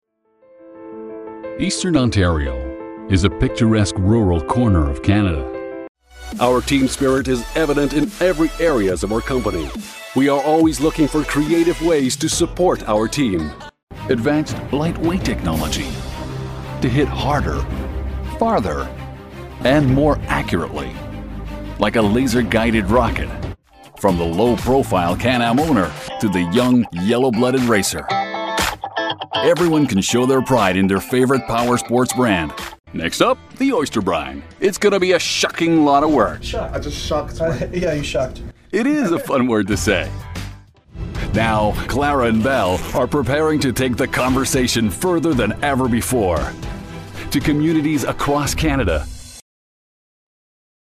Bilingual, English and French Canadian Male voice over (voice off) and narrator for adversting, documentaries and corporate videos
Sprechprobe: Sonstiges (Muttersprache):